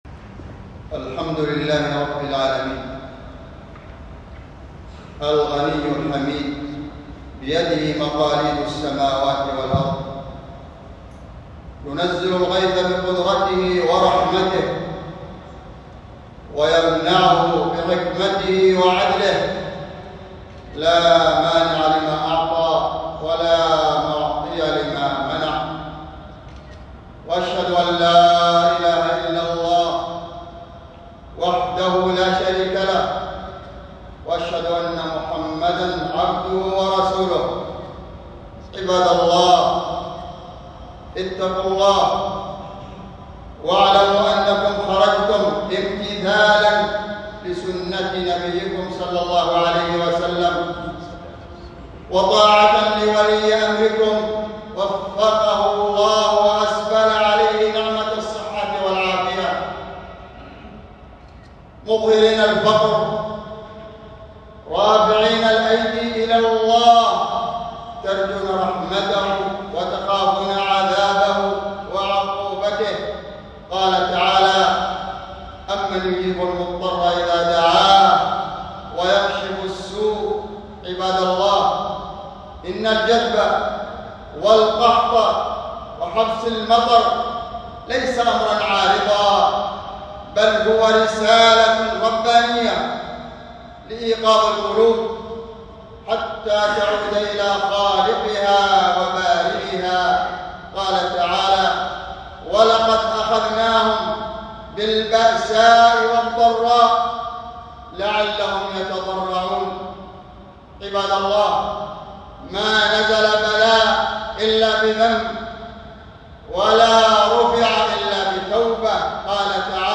معلومات الملف ينتمي إلى: الخطب خطبة صلاة الاستسقاء ..